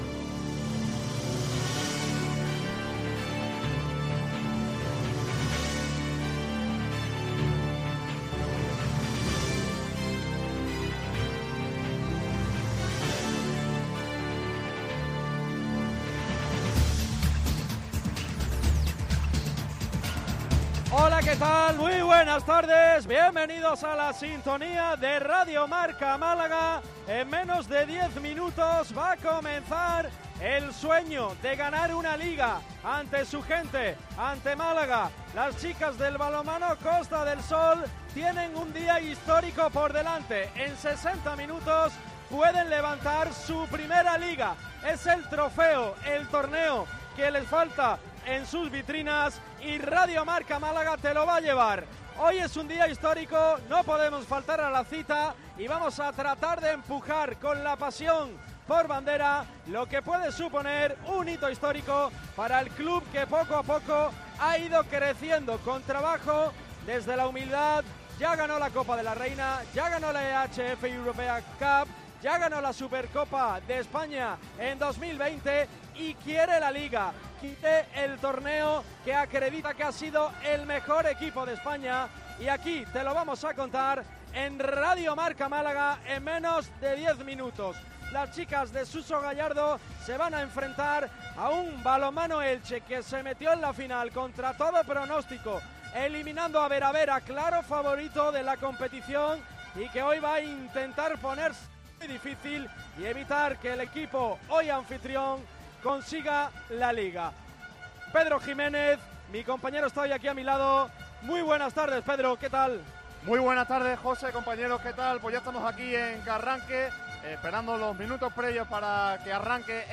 Casi dos horas de retransmisión